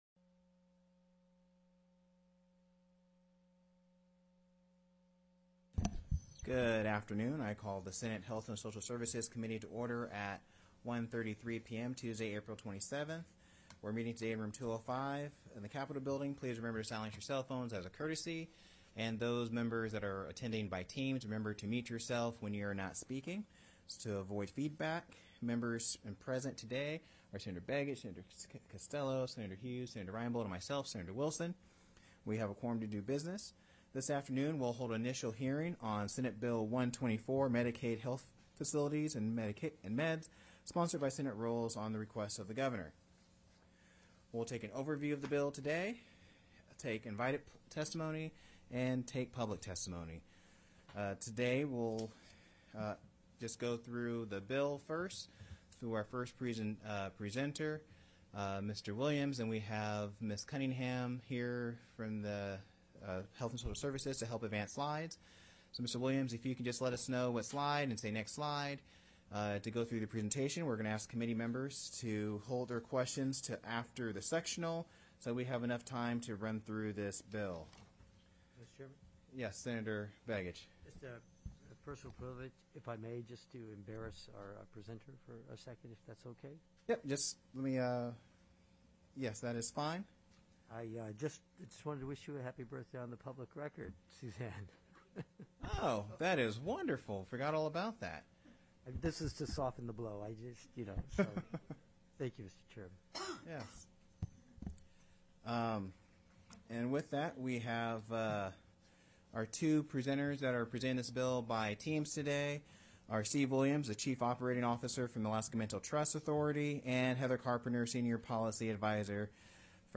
The audio recordings are captured by our records offices as the official record of the meeting and will have more accurate timestamps.
Heard & Held -- Invited & Public Testimony